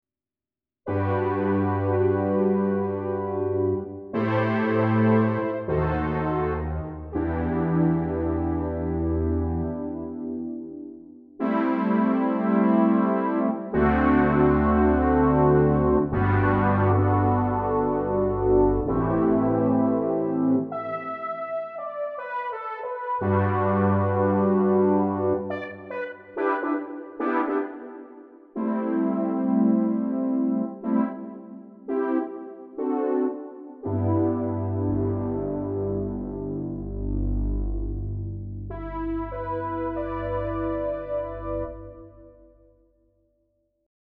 Within a week I have made these sounds, and recordings of them, using 24 bit 44.1kHz TOS link:
brasssynthtv.mp3